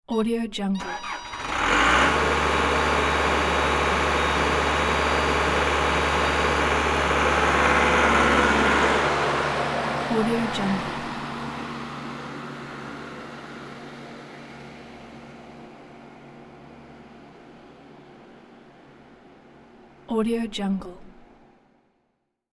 Bobcat Start And Pull Away Bouton sonore